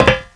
FOOTSTEP
1 channel
FOOTWLK3.WAV